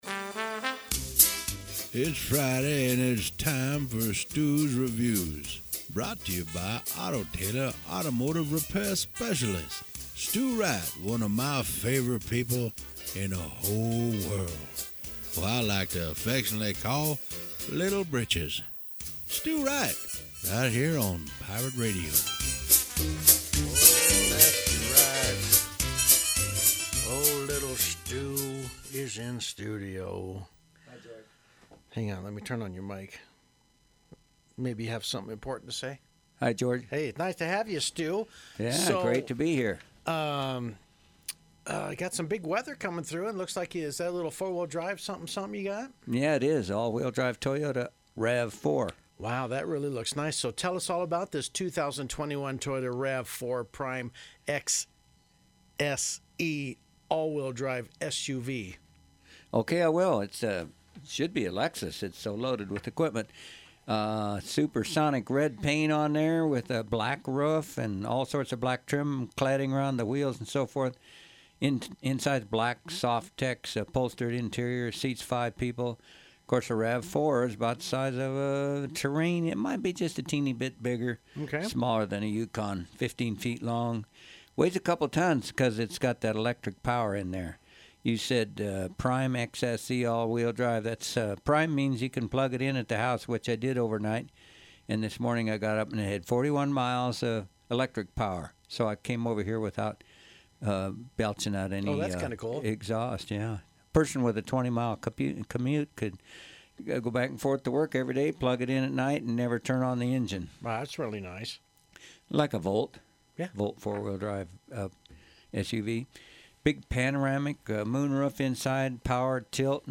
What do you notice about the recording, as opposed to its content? Radio review at the Pirate